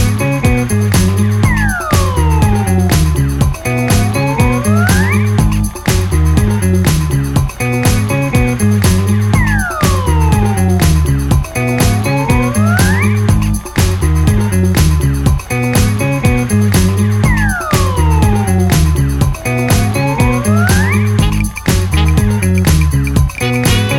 no Backing Vocals Dance 3:53 Buy £1.50